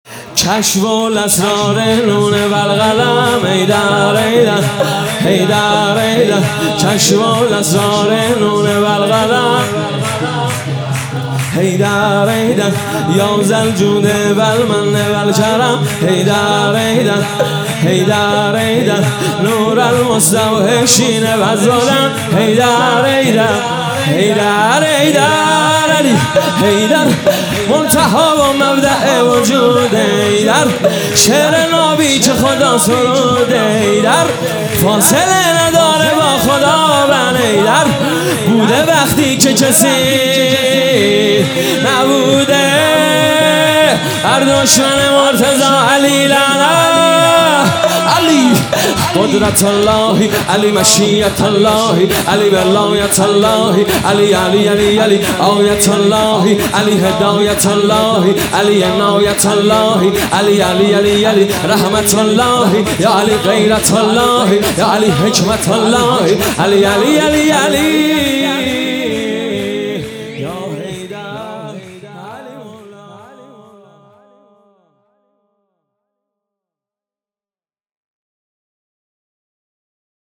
شور | حیدر شعر نابی که خدا سروده
شب ولادت امیرالمؤمنین حضرت علی